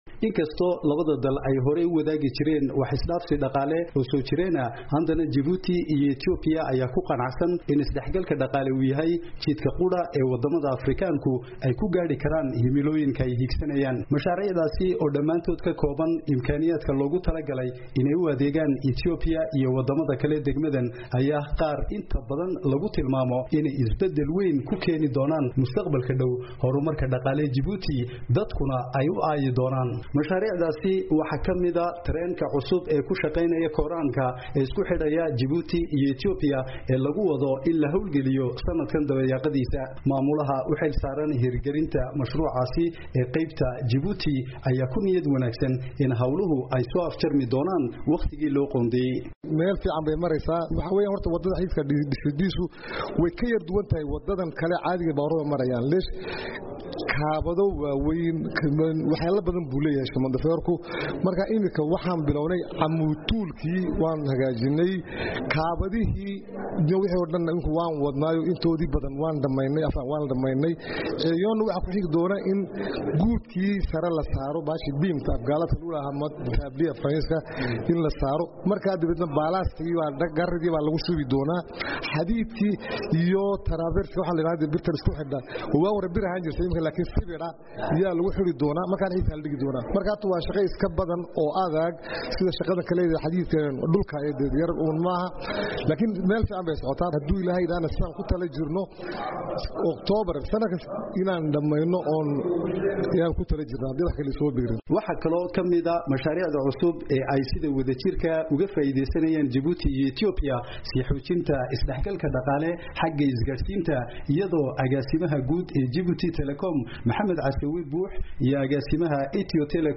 Warbixin: Xiriirka Jabuuti iyo Ethiopia